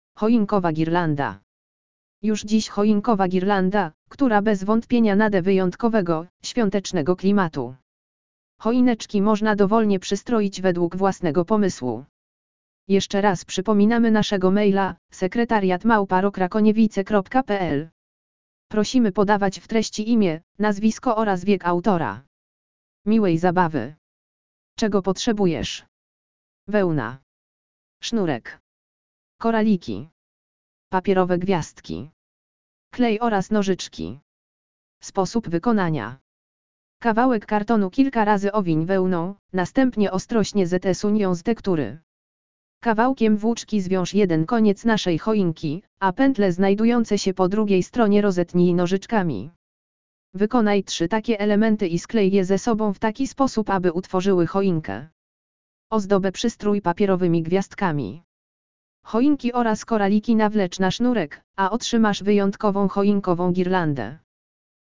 audio_lektor_choinkowa_girlanda.mp3